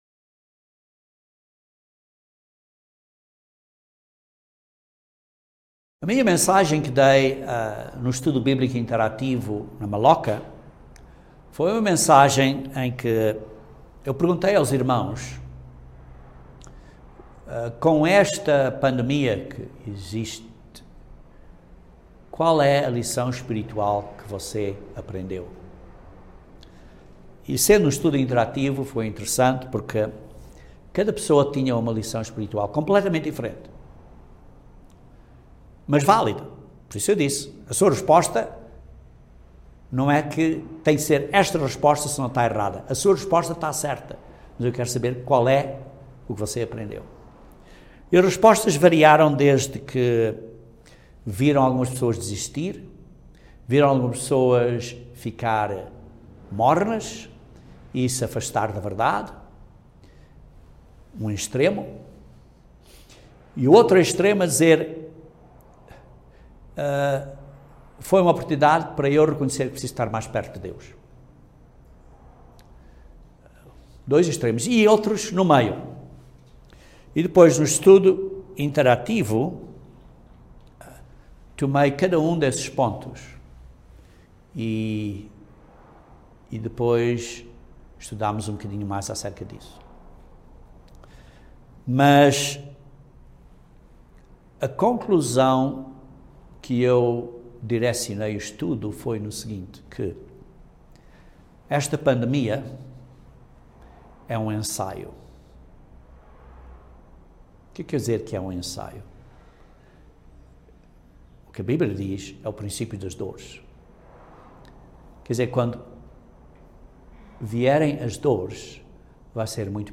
Neste mundo de dificuldades temos uma inclinação a nos culparmos e assim ficamos desencorajados. Este sermão descreve como o sacrifício de Cristo nos aperfeiçoou se estamos sendo santificados neste Caminho Cristão pela Obra do poder de Deus.